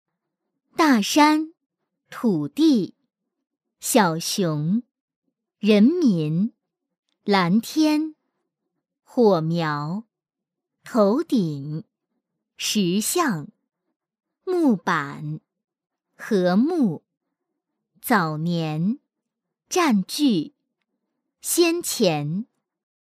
女218-动画课 橙橙识字
女218-温柔甜美 温柔知性
女218-动画课 橙橙识字.mp3